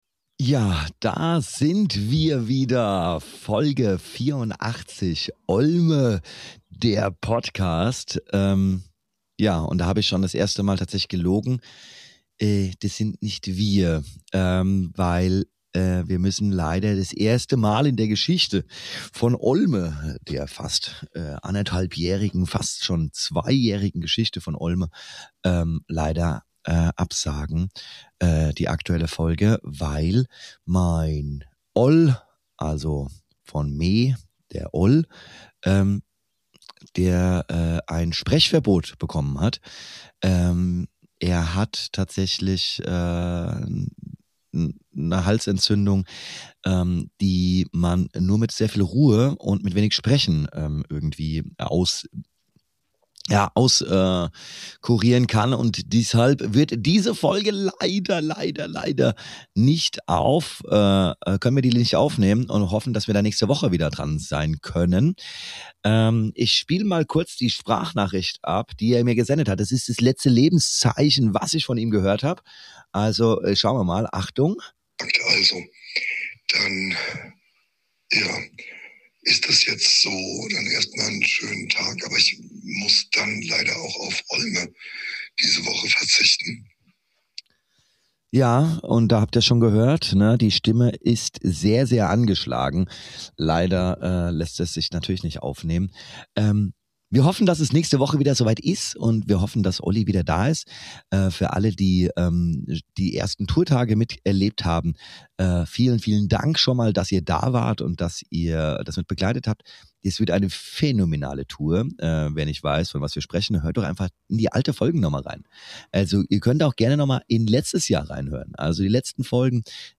heute eine kleine Solo-Folge